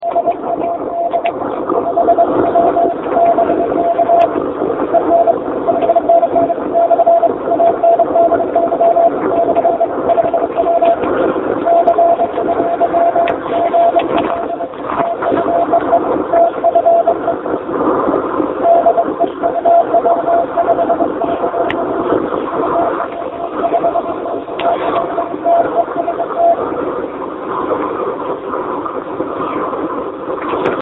RU-QRP Club. 80м практически никого не было слышно... Зато на 40 и 20 вполне, так...